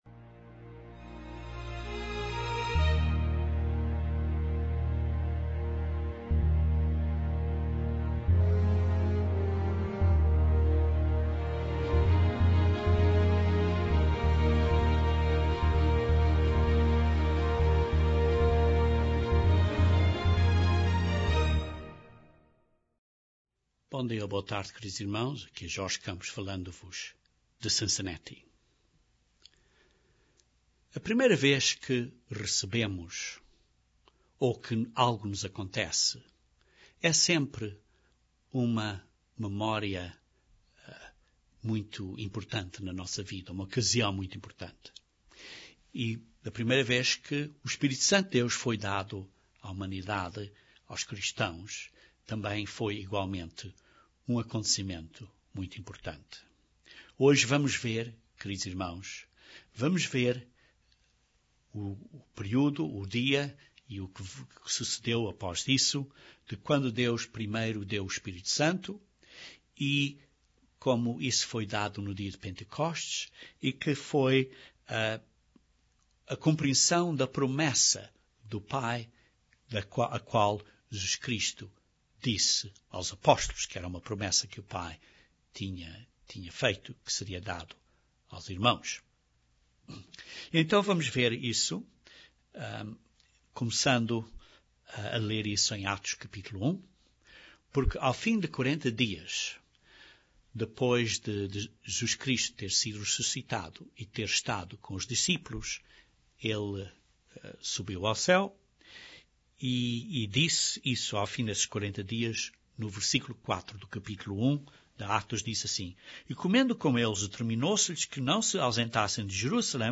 Este sermão descreve alguns princípios importantes do dom do Espírito de Deus.